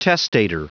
Prononciation du mot testator en anglais (fichier audio)